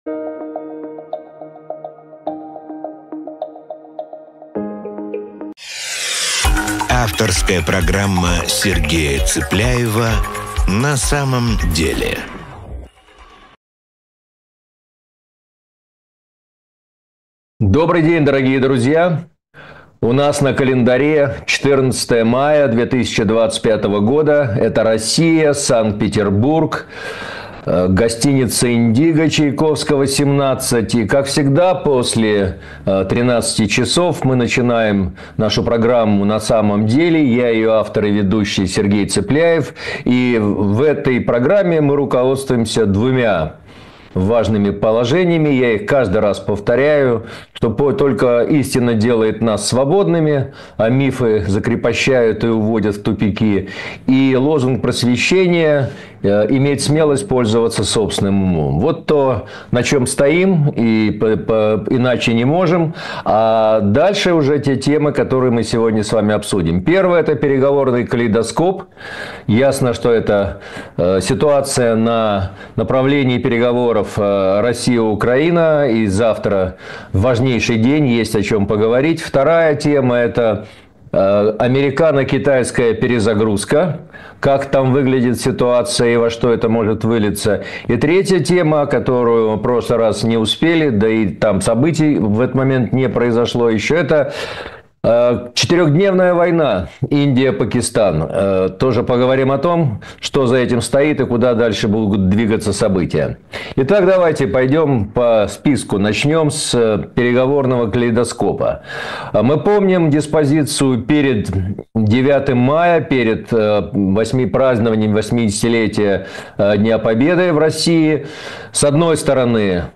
Эфир ведёт Сергей Цыпляев